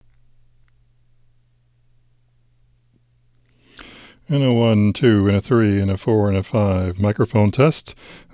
noisetest2-raw.wav